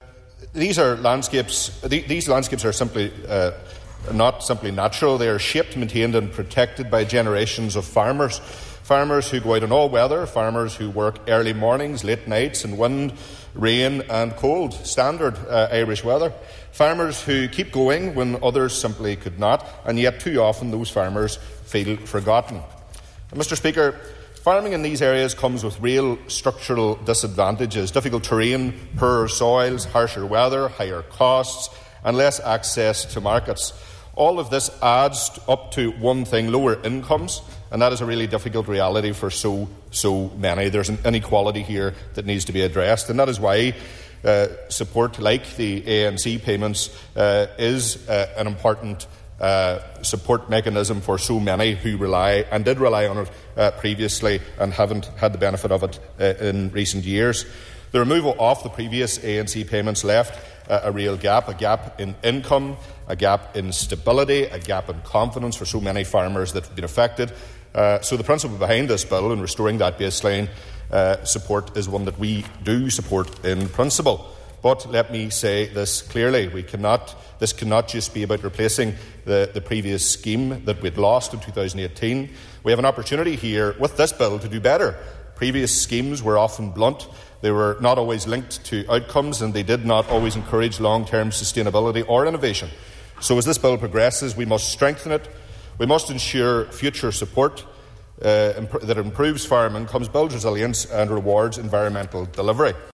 Mr McCrossan says payments delivered under the ANC Bill are an important mechanism: